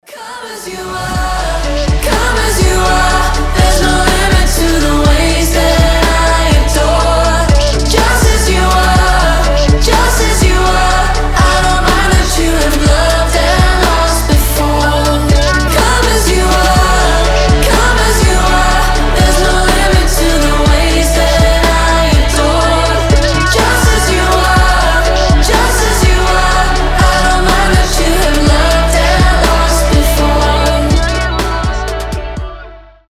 мелодичные
дуэт
медленные
indie pop
alternative